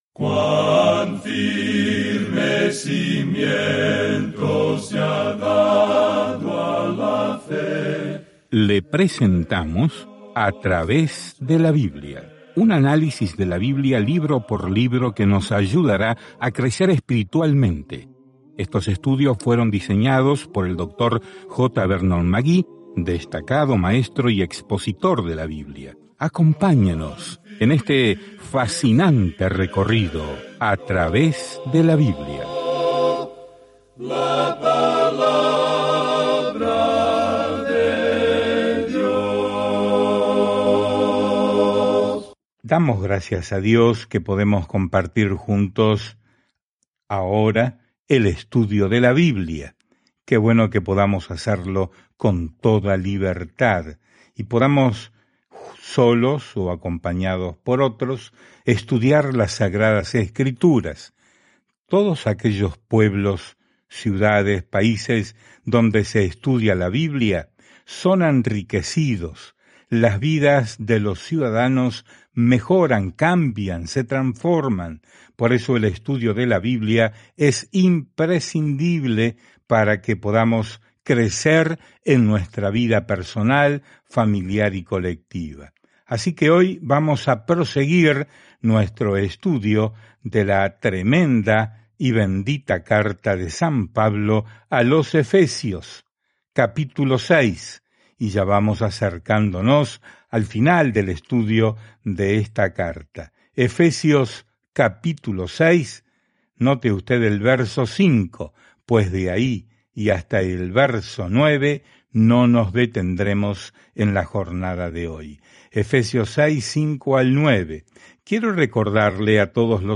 Escrituras EFESIOS 6:5-9 Día 24 Comenzar este Plan Día 26 Acerca de este Plan Desde las hermosas alturas de lo que Dios quiere para sus hijos, la carta a los Efesios explica cómo caminar en la gracia, la paz y el amor de Dios. Viaja diariamente a través de Efesios mientras escuchas el estudio en audio y lees versículos seleccionados de la palabra de Dios.